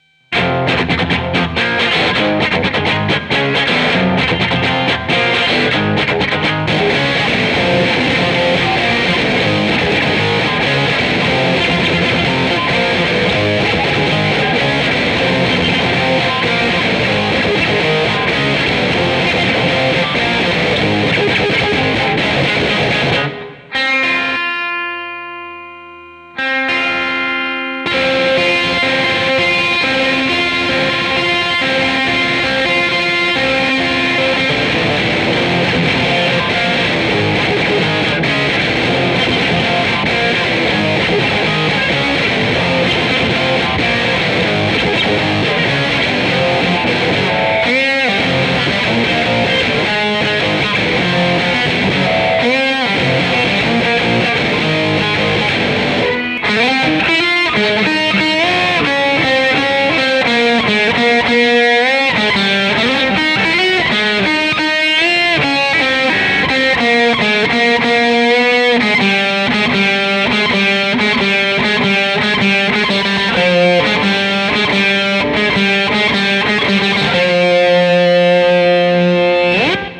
Treble 10, mid similar, bass lower.
In the first clip, the tuning is very, very obvious.